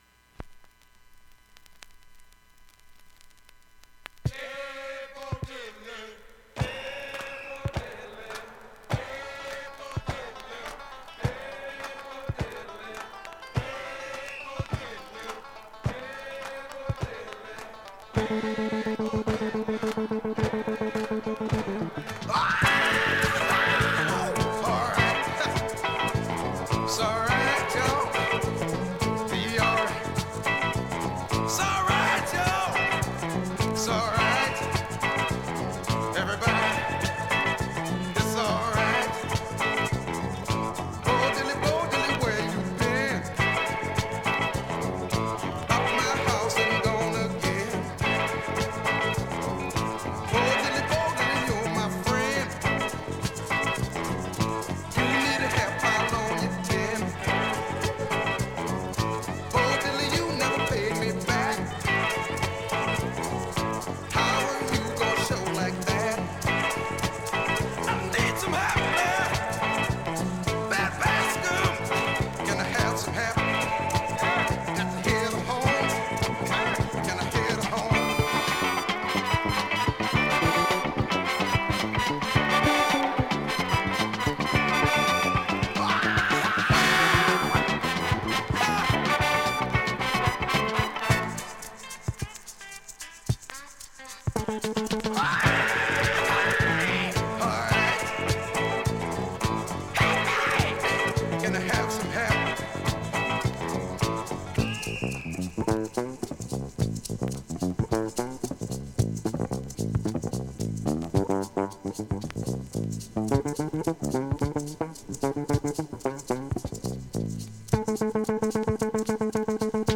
◆盤質Ａ面/ほぼEX+ stereo